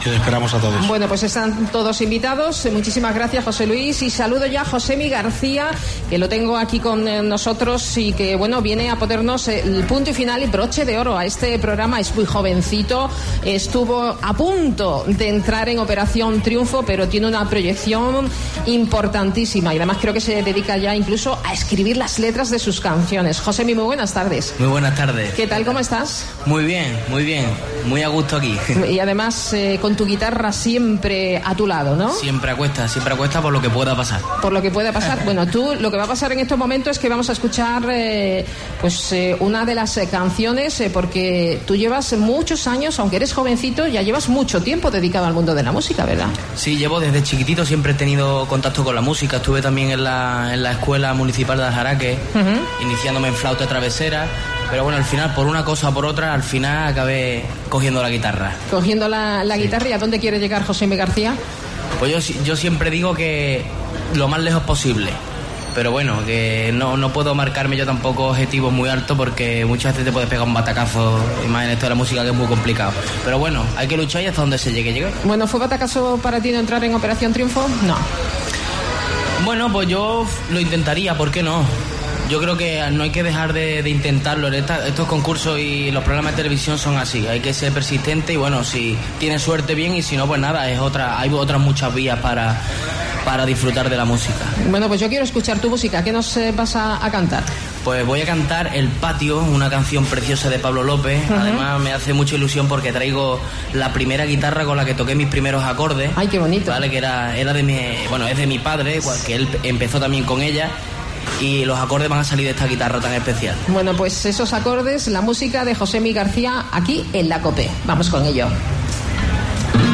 AUDIO: Artista onubense actuando en programa de Colombinas " Herrera en COPE"